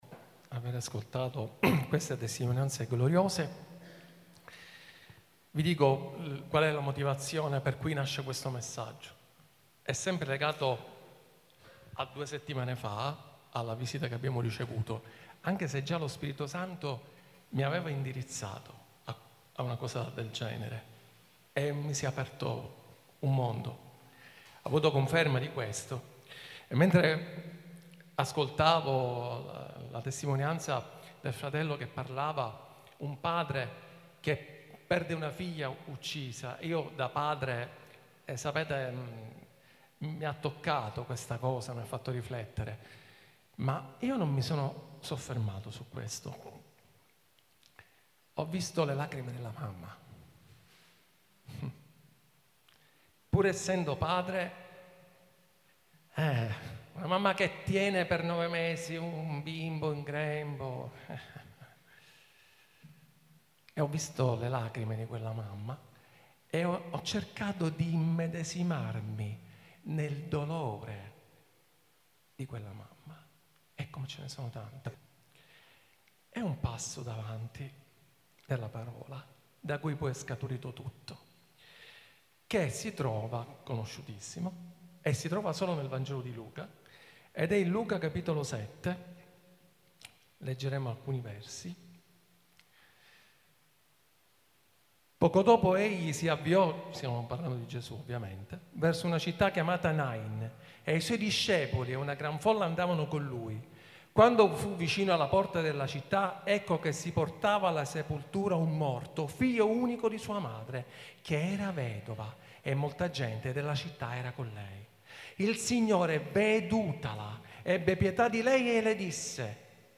15/06/2025 – Worship Service RdM